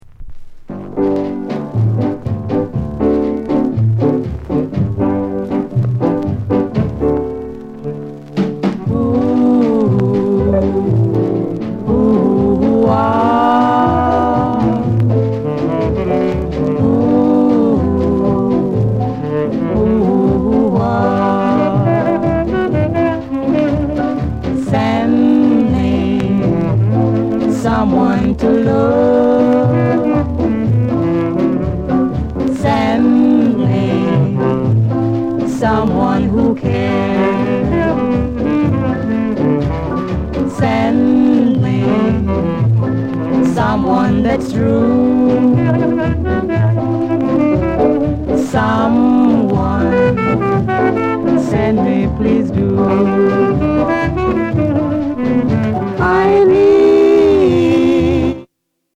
JAZZY INST